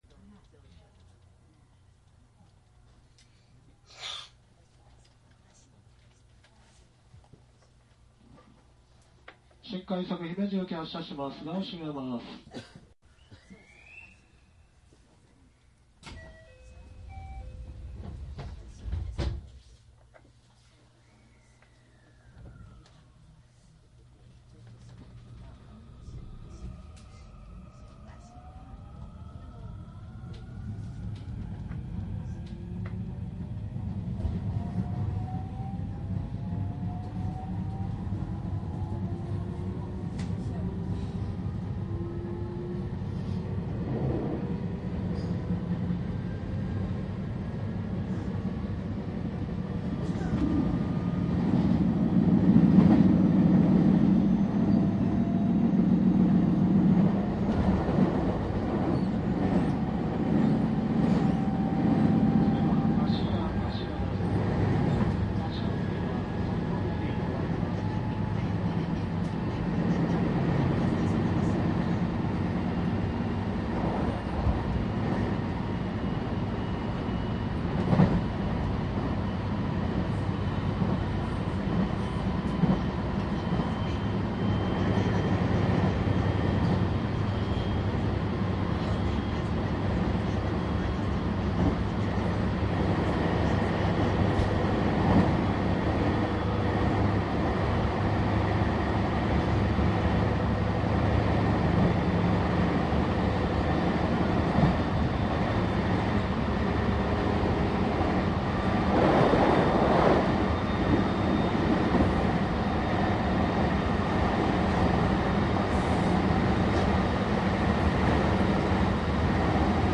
西日本223系★新快速 鉄道走行音CD★
京都～大阪は客が多かったので会話がところどころ聞こえます。大阪から先の車両はフラットがかなり凄いですが、十分楽しめる内容に仕上がってると思います。
※収録機材は、ソニーDATと収録マイクソニーECM959を使用しております。